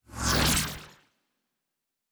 pgs/Assets/Audio/Sci-Fi Sounds/Doors and Portals/Teleport 1_2.wav at master
Teleport 1_2.wav